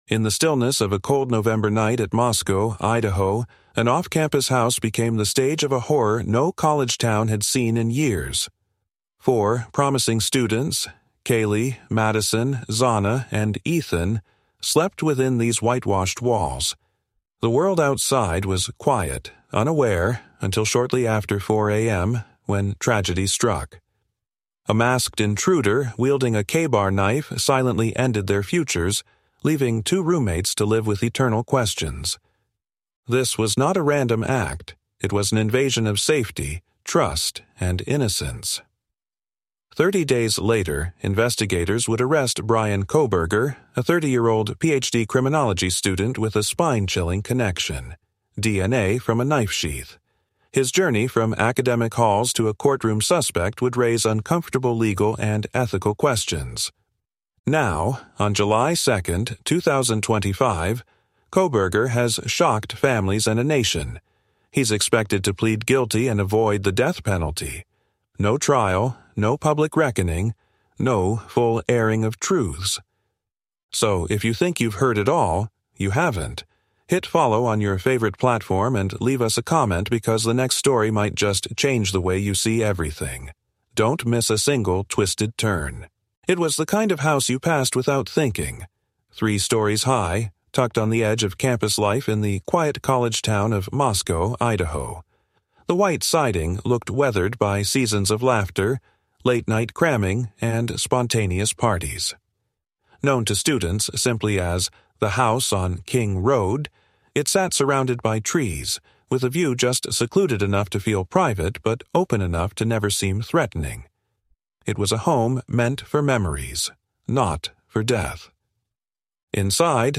Told through a mix of haunting narration and deep research, this series explores the victims’ lives, the eerie behavior of the accused, the forensic puzzle investigators faced, and the legal aftermath that